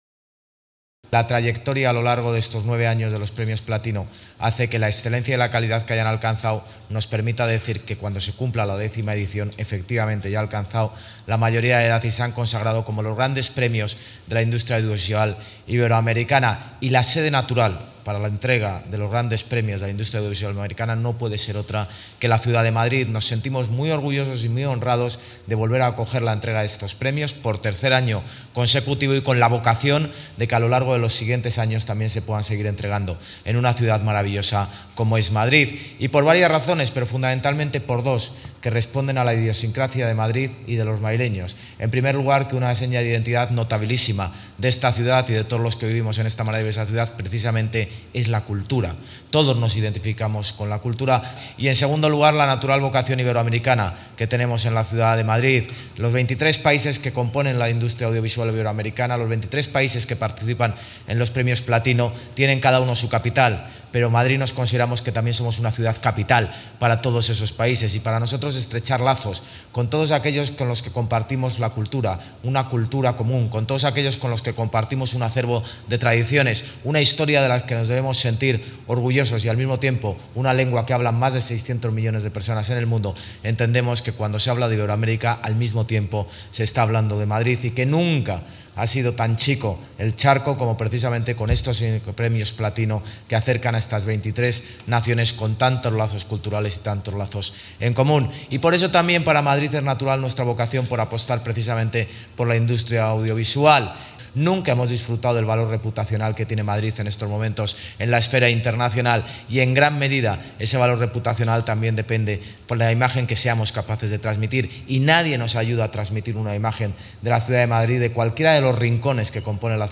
Nueva ventana:Declaraciones del alcalde de Madrid, José Luis Martínez-Almeida